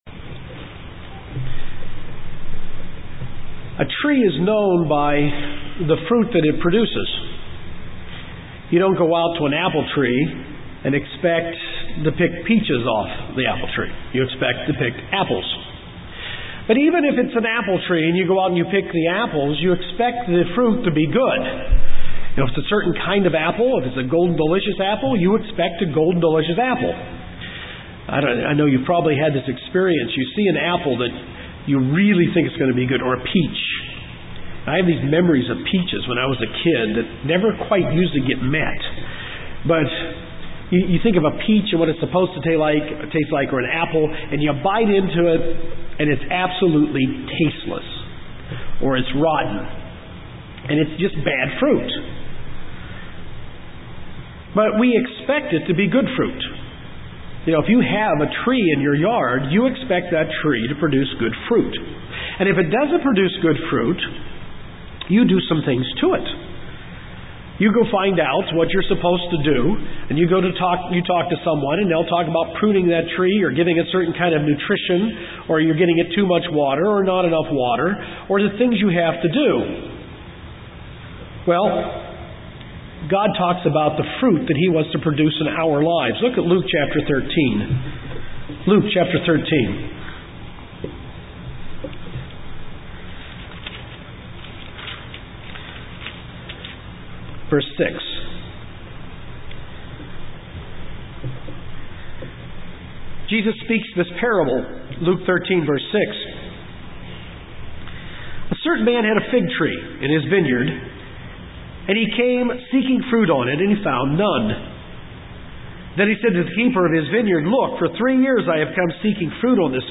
This is the second in a series of sermons on the fruits of the spirit. The series is beginning at the end of the list of fruits in Galatians 5:22-23.